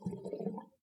bathroom-sink-04
bath bathroom bubble burp click drain dribble dripping sound effect free sound royalty free Sound Effects